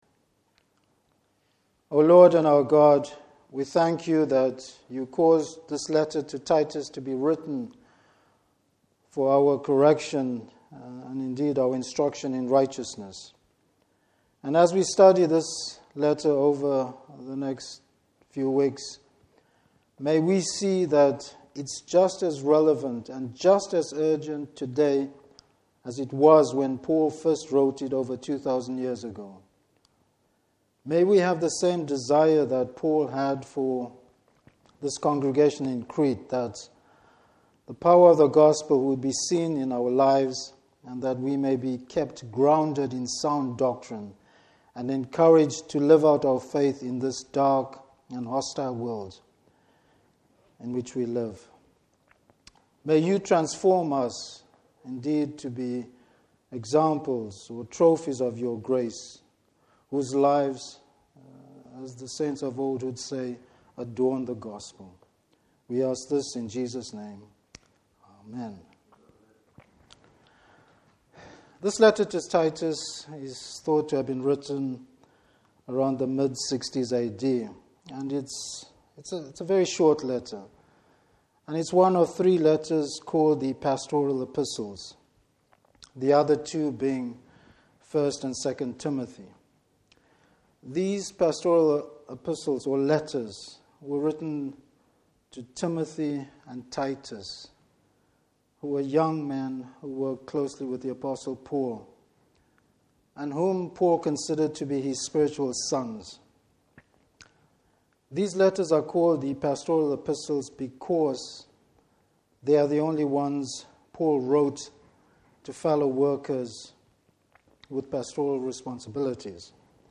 Service Type: Evening Service Paul opens his letter with a summery of the Gospel.